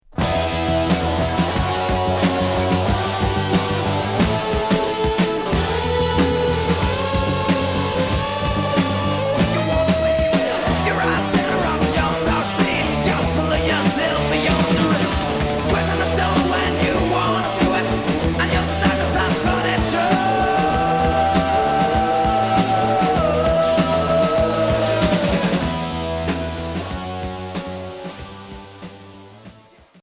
Rock fusion Unique 45t retour à l'accueil